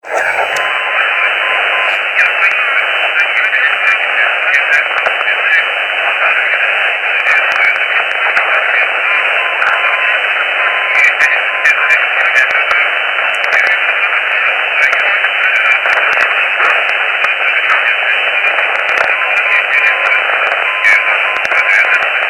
620 кГц. UB ДПРМ ?
Неопознанный ДПРМ. на опознание.